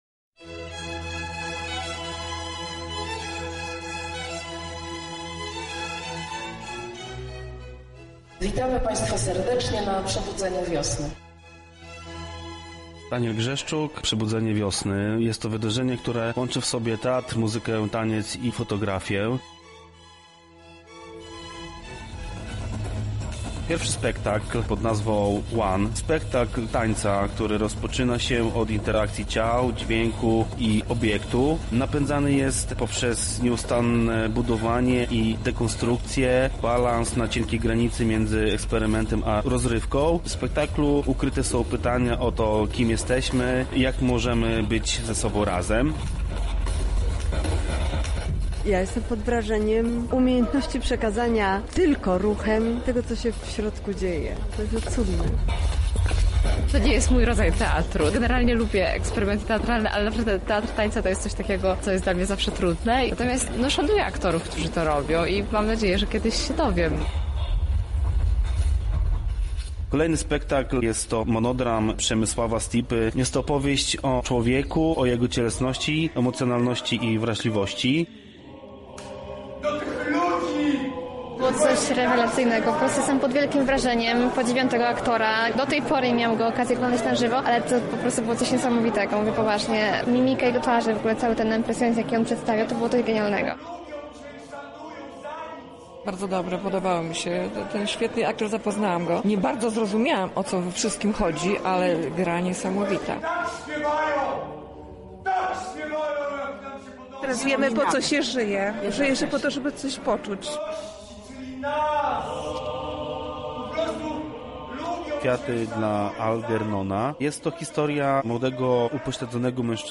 Posłuchajcie relacji naszej reporterki: